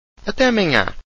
Até amanhã   Atay aman[ng]yah – lit. ‘until tomorrow’ – used even if you won’t actually see the person for a few days.